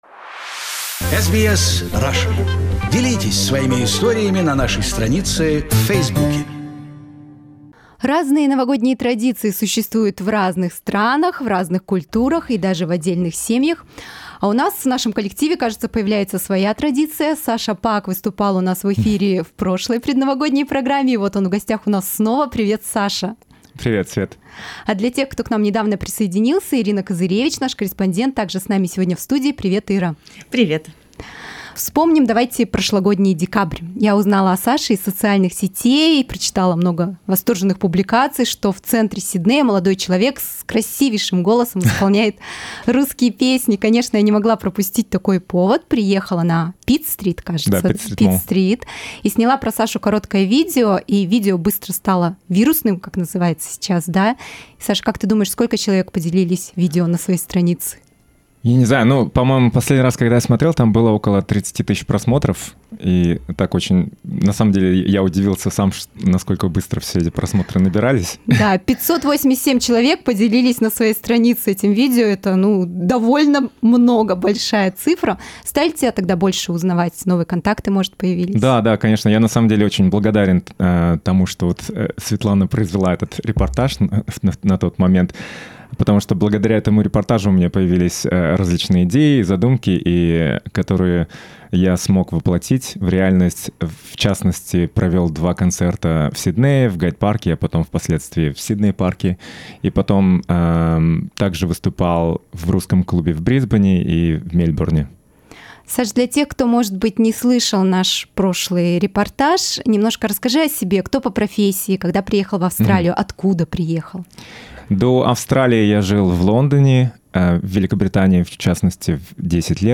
Слушайте интервью из прямого эфира программы за 30 декабря.